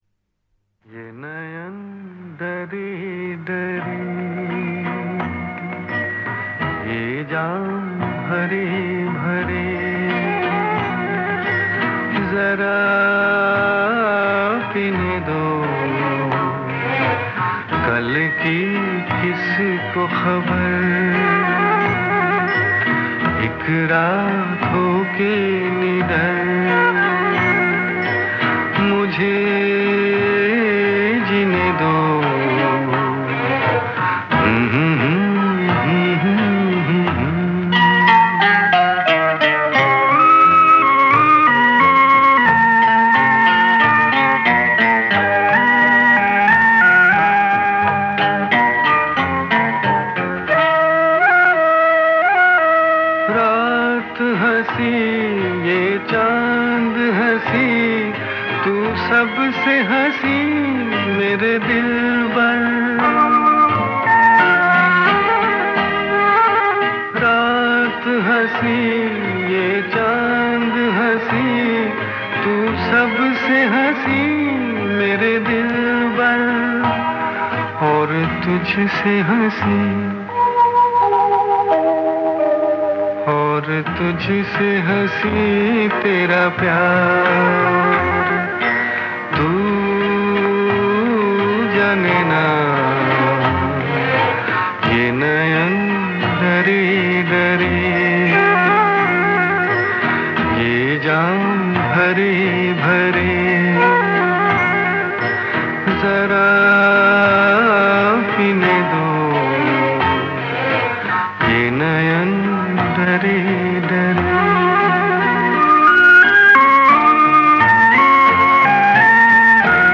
This haunting melody was composed and sung by the